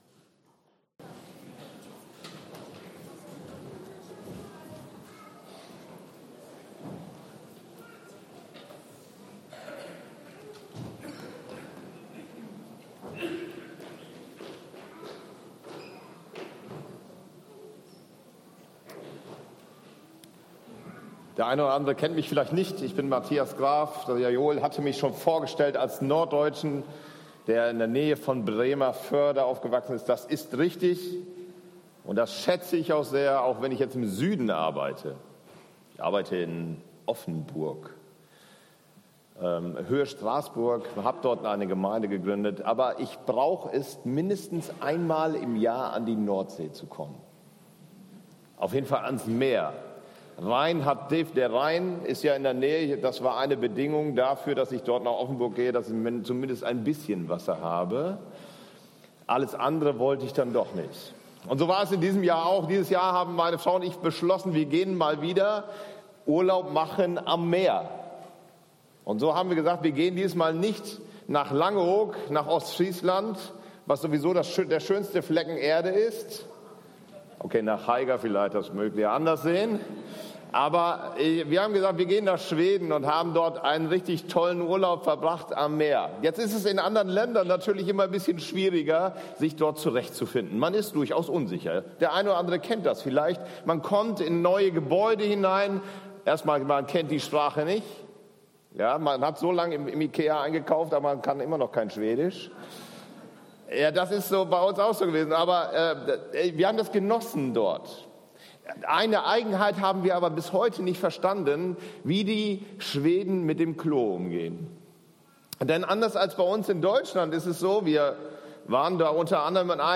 Von der Ehre gerettet zu sein! ~ EFG-Haiger Predigt-Podcast Podcast